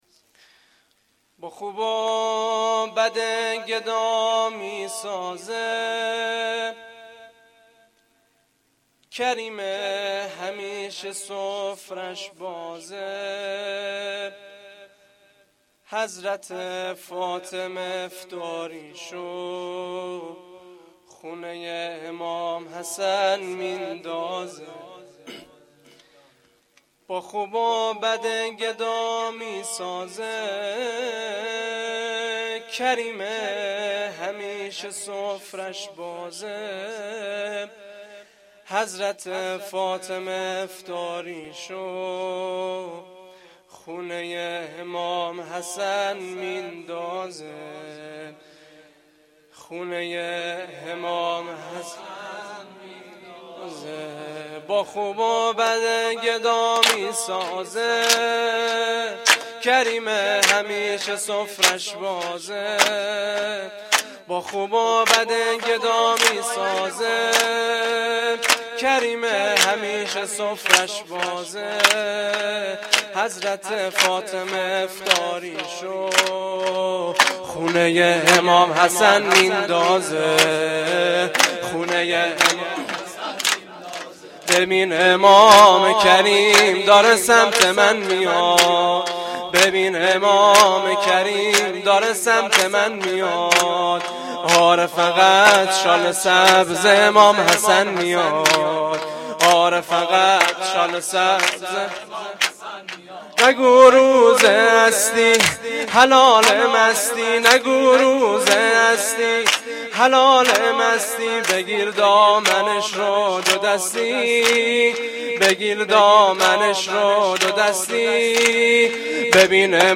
سرود
sorood-Rozatol-Abbas.Milad-Emam-Hasan.mp3